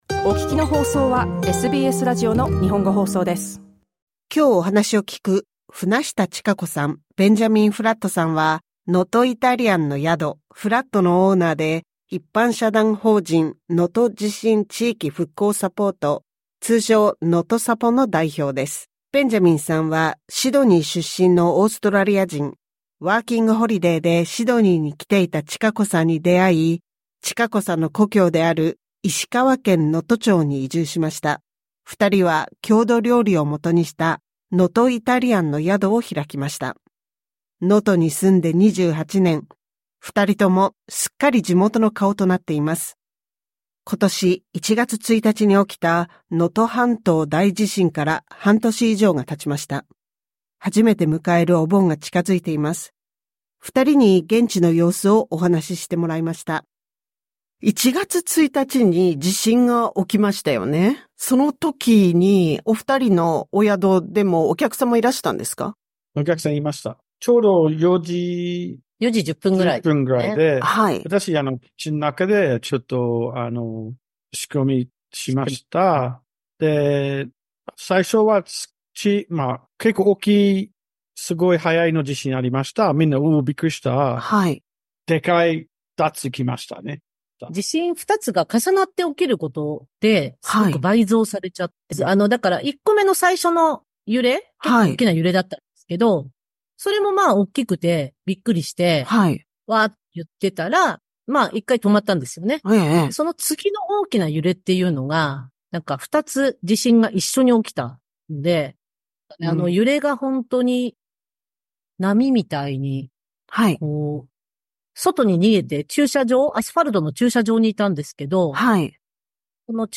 詳しくはインタビューをオーディオで聞いてください。